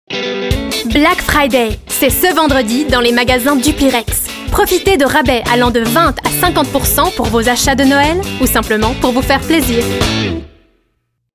Radio Commercial FR
Upbeat promotion announcing special Black Friday discounts at Duplirex.
FR-Commercial-Black Friday.mp3